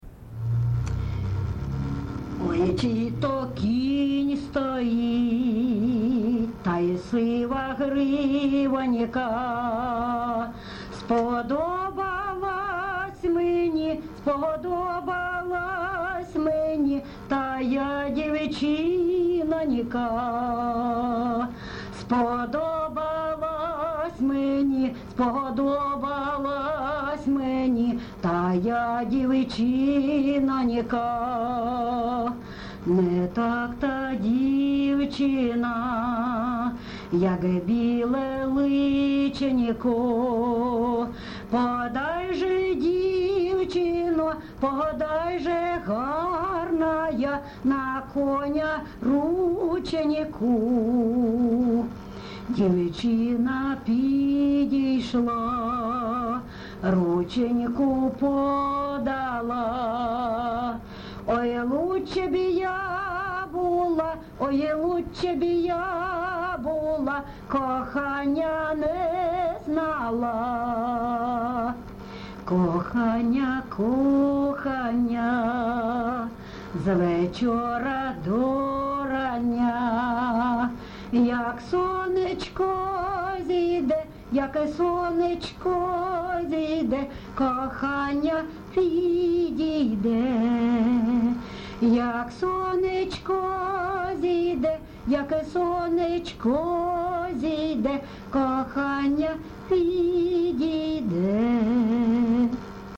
ЖанрПісні з особистого та родинного життя, Пісні літературного походження
Місце записус. Лозовівка, Старобільський район, Луганська обл., Україна, Слобожанщина